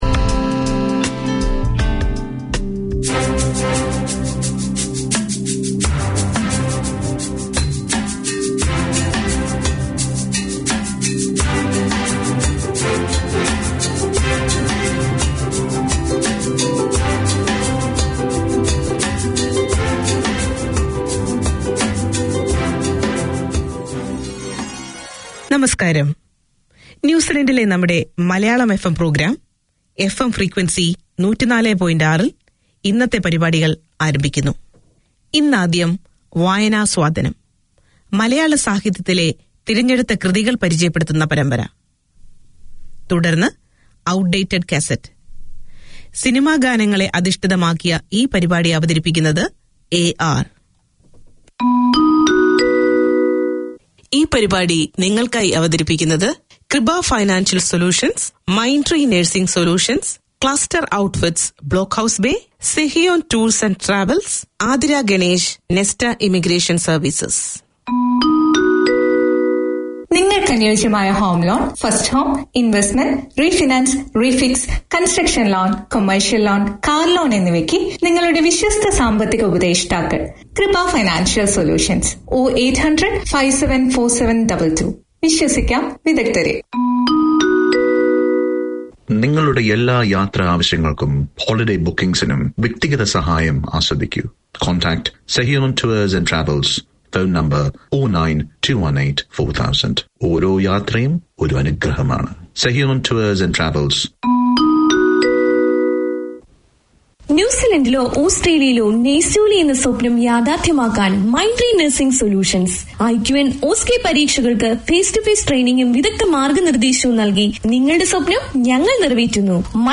Hear dramas and stories based on Malayalam songs on Fridays, film and music on Saturdays, and enjoy a talk-based show featuring discussions and interviews on the Sunday edition.
Malayalam FM 7:25pm FRIDAY Community magazine Language: Malayalam A celebration of Malayalam language, literature, music and culture; Malayalam FM presents three weekly programmes.